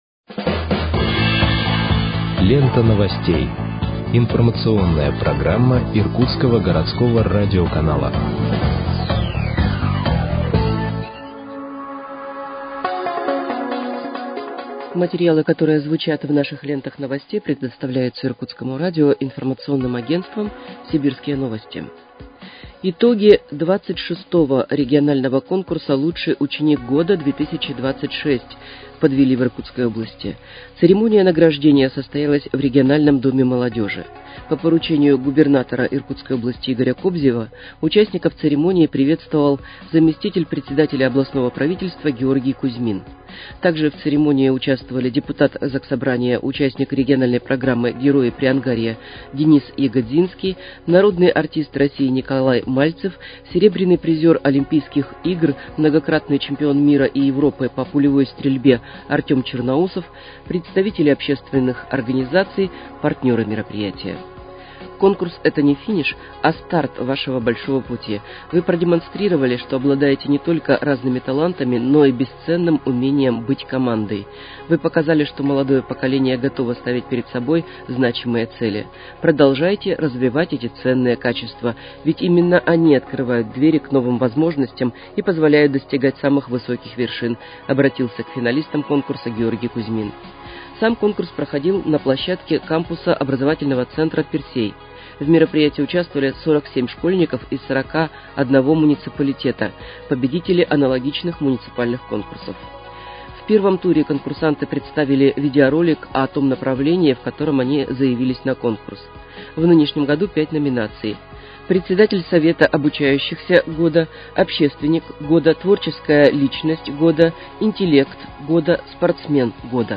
Выпуск новостей в подкастах газеты «Иркутск» от 20.04.2026 № 1